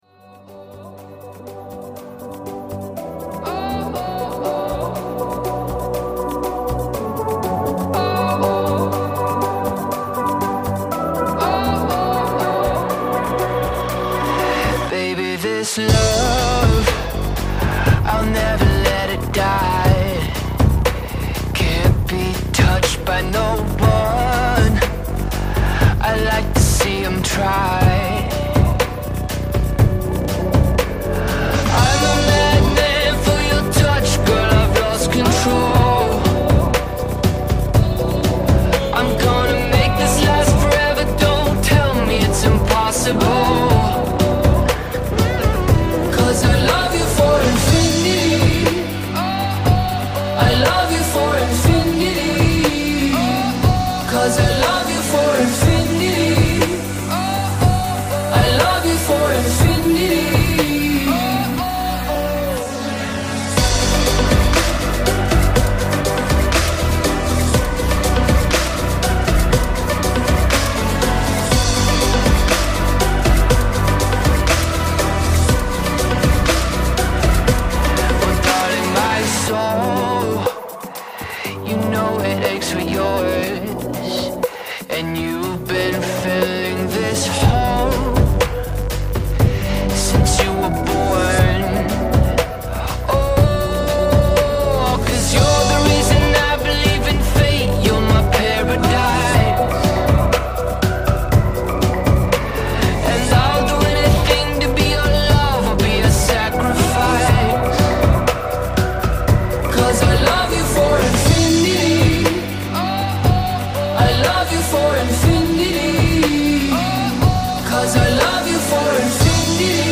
Voo Drone DJI NEO 08/25 Sound Effects Free Download